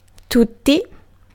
Ääntäminen
Vaihtoehtoiset kirjoitusmuodot (rikkinäinen englanti) orl Synonyymit completely everything Ääntäminen US : IPA : /ˈɔl/ UK : IPA : /ˈɔːl/ cot-caught: IPA : [ɑɫ] Northern Cities Vowel Shift: IPA : [ɑɫ] Tuntematon aksentti: IPA : /ˈɑl/